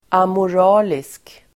Ladda ner uttalet
Uttal: [amor'a:lisk]